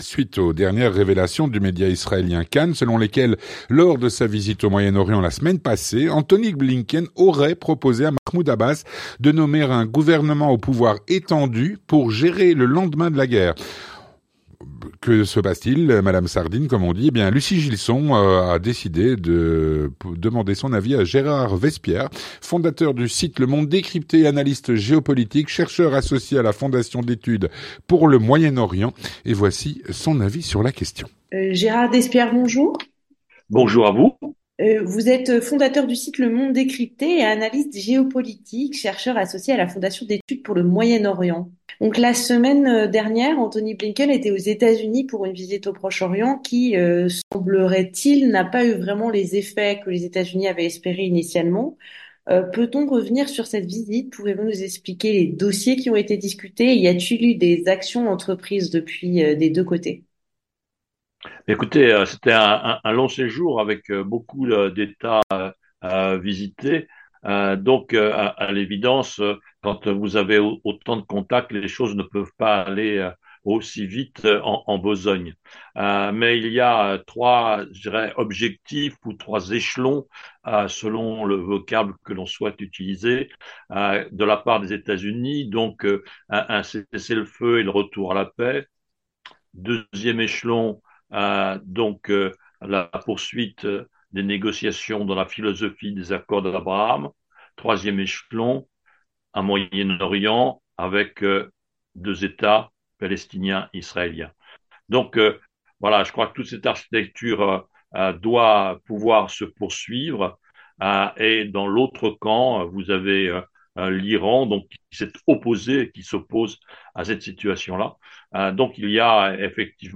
L'entretien du 18H - Les USA auraient proposé à Mahmoud Abbas la gouvernance de Gaza après la guerre.